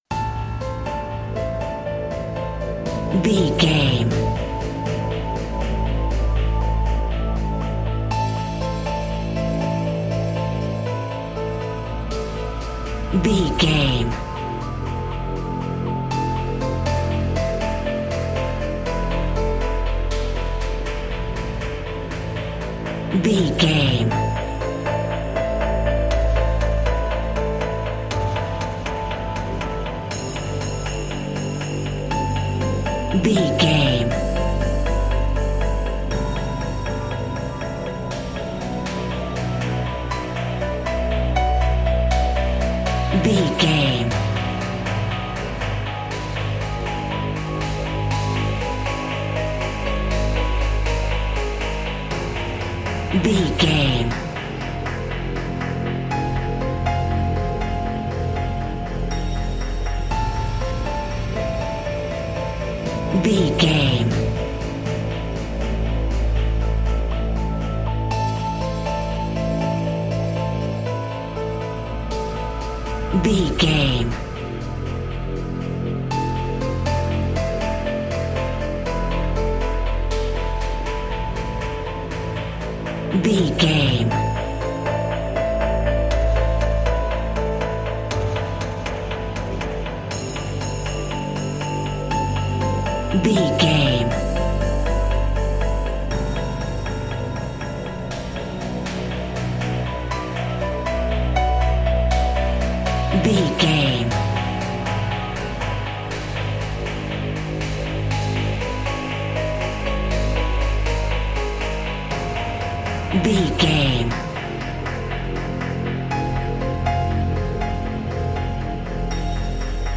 Aeolian/Minor
strings
drum machine
synthesiser
piano
ominous
dark
haunting
tense
creepy